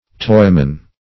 Toyman \Toy"man\ (toi"man), n. One who deals in toys.